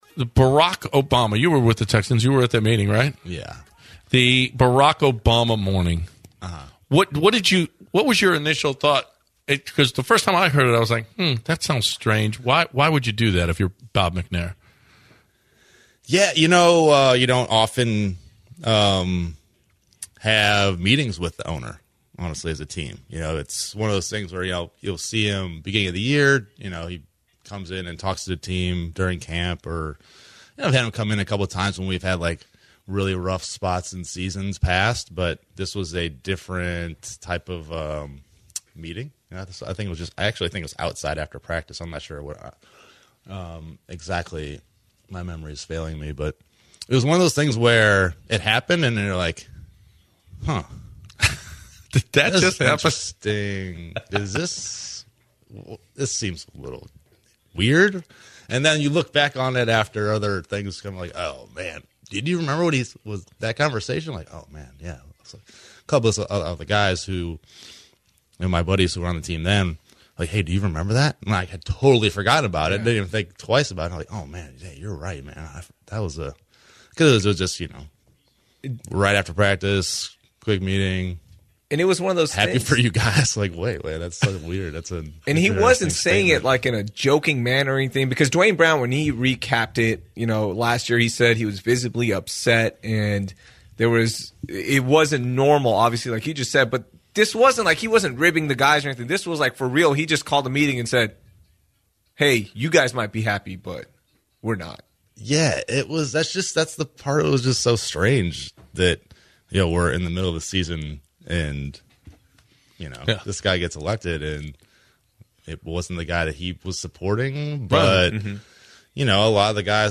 Former Texan Owen Daniels was on ESPN 97.5 not that long ago and spoke about the strange meeting Bob McNair held with the players after the election of President Barack Obama in 2008.